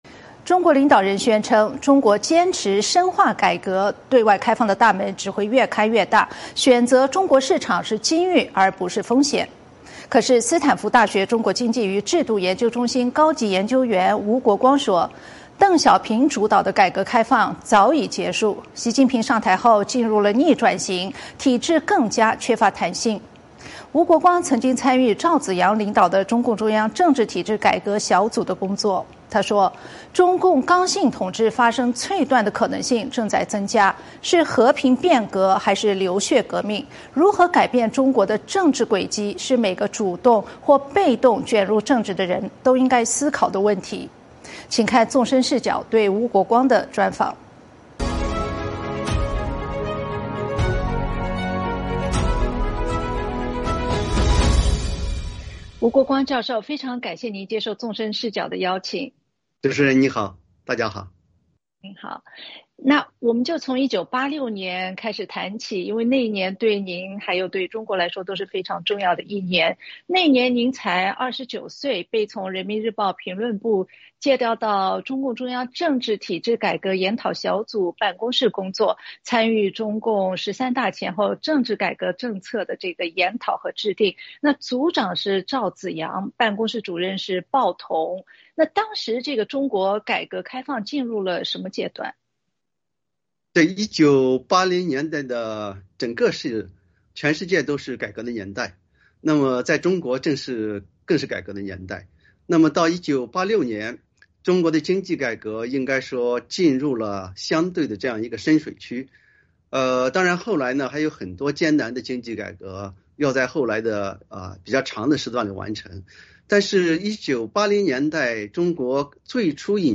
《纵深视角》节目进行一系列人物专访，受访者发表的评论不代表美国之音的立场。